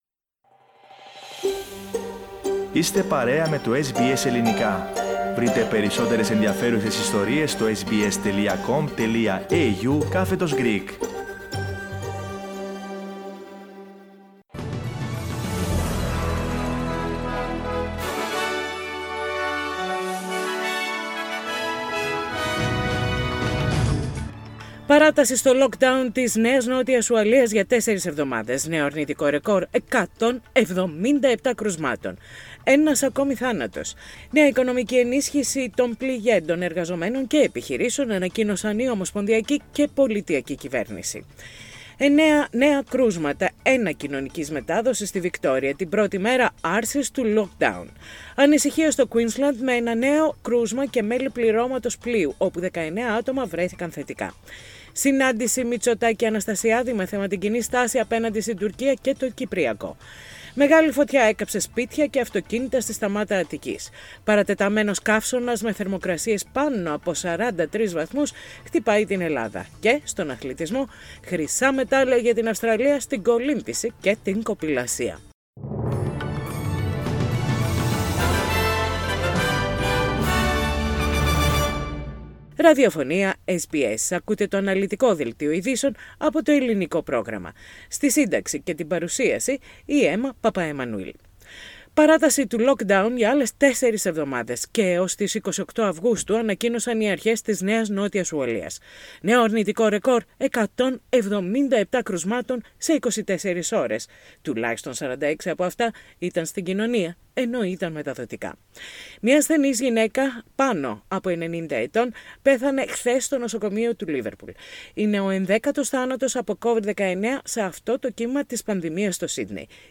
Main news of the day from SBS Radio Greek.